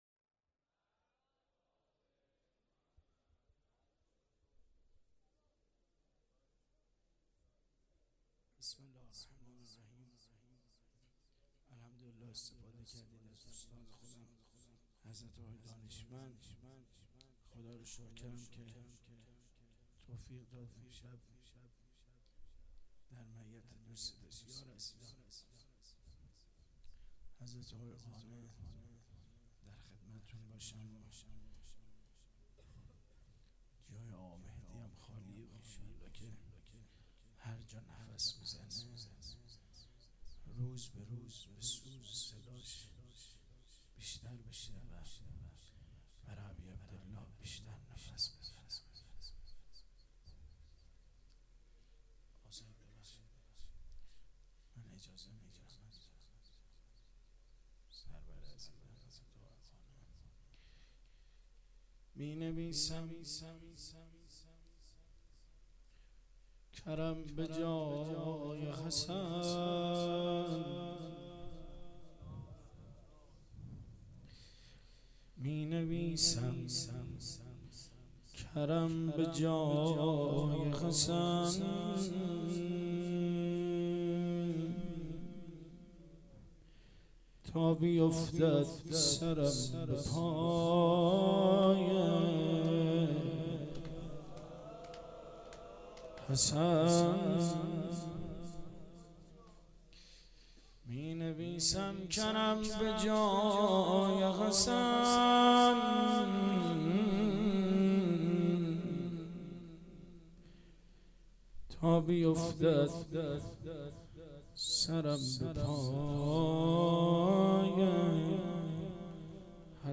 مراسم شب 28 صفر 95 شام شهادت رسول الله(ص) و امام حسن مجتبی(ع)